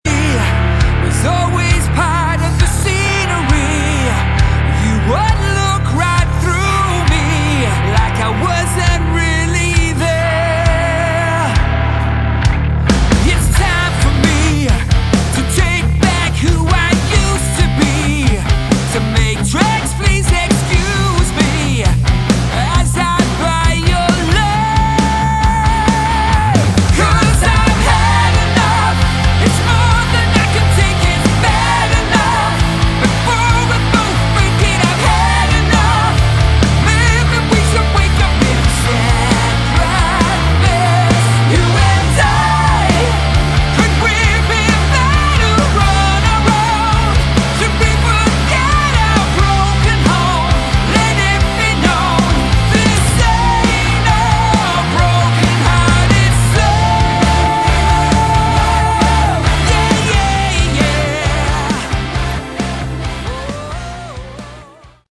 Category: Melodic Rock
vocals
bass
keyboards
guitars
drums